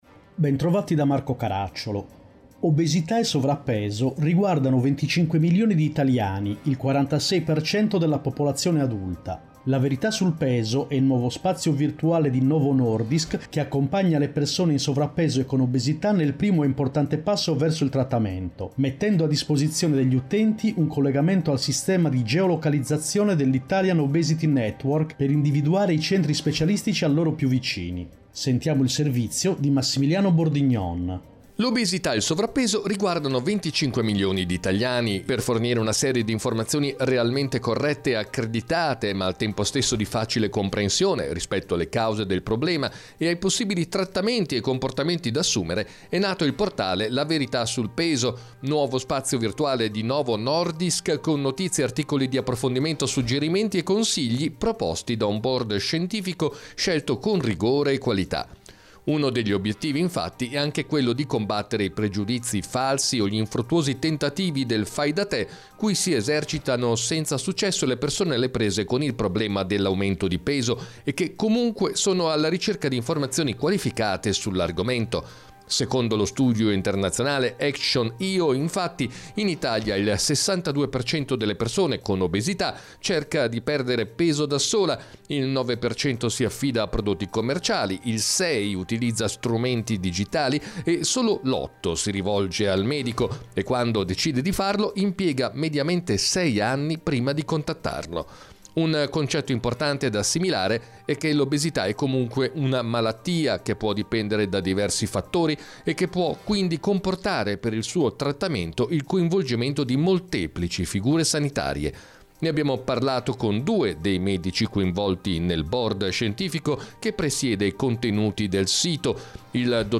Puntata con sigla